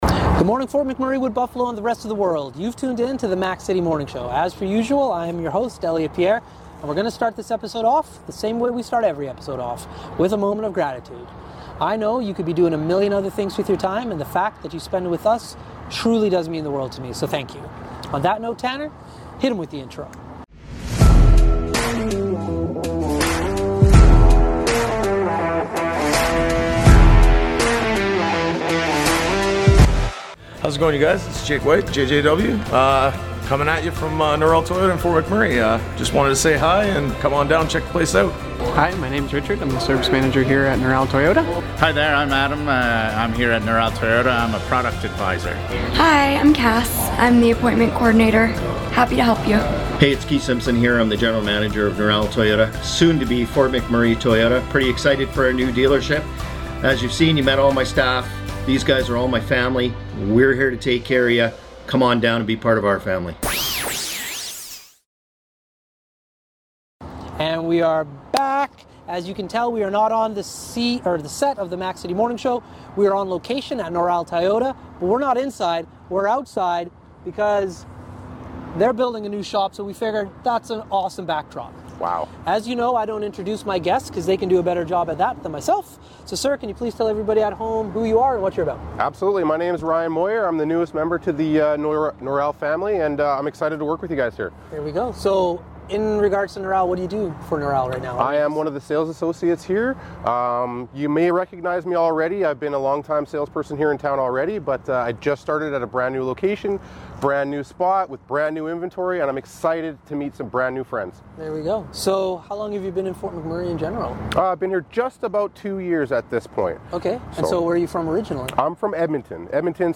On location in front of the construction of the brand new building